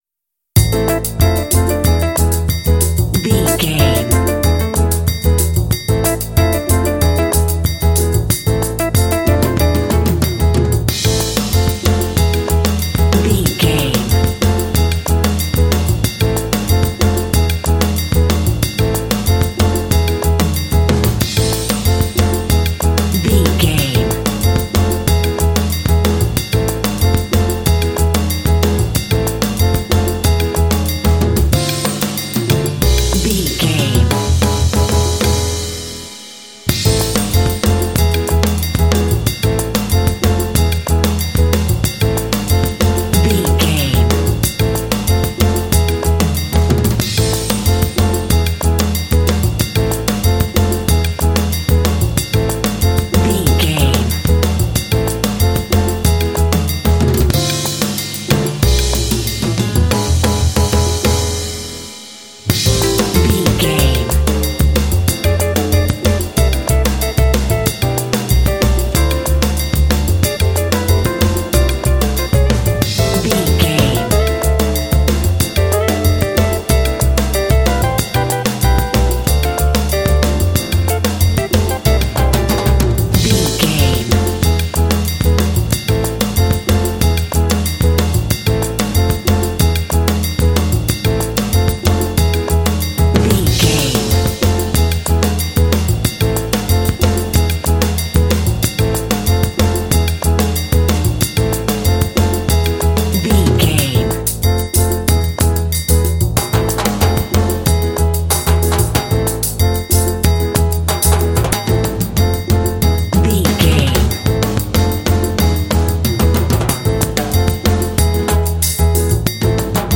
Uplifting
Aeolian/Minor
cool
smooth
percussion
drums
electric guitar
piano
bass guitar
trumpet
conga
latin
Lounge
downtempo